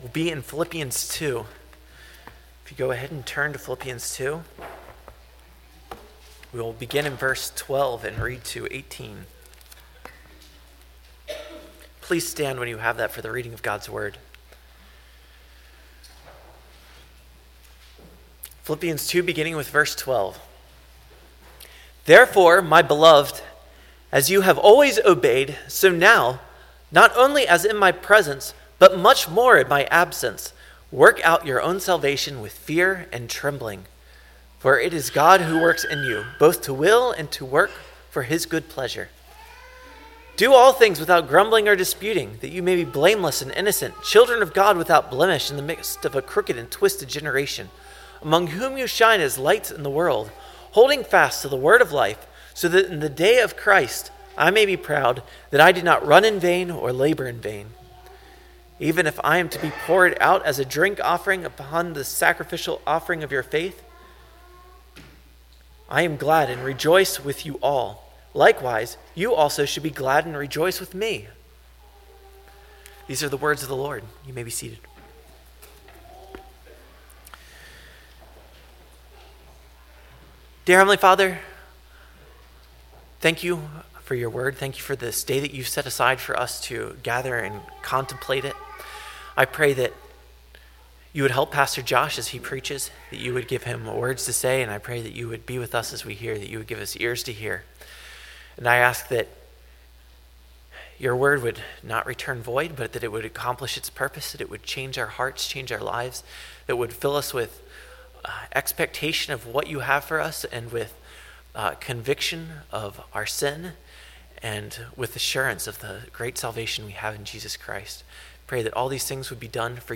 Sermon